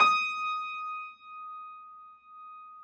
53h-pno19-D4.wav